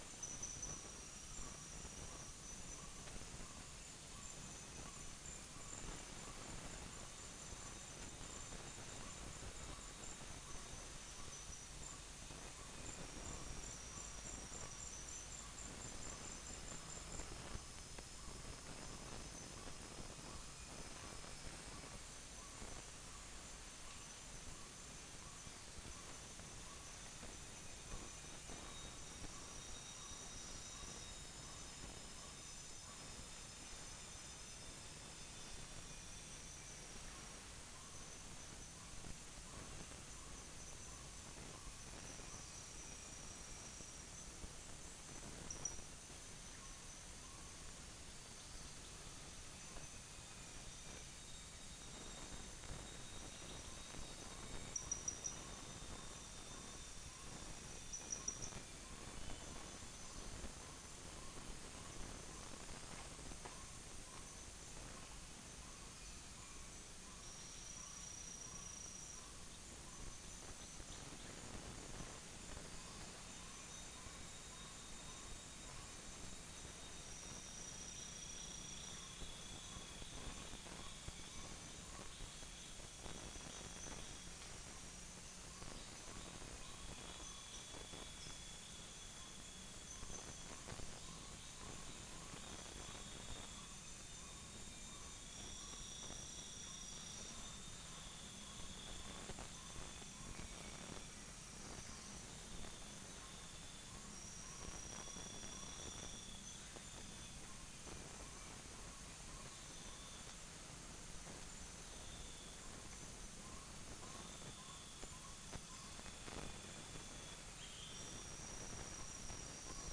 Upland plots dry season 2013
Stachyris maculata
Malacopteron magnirostre
Orthotomus atrogularis
Aegithina viridissima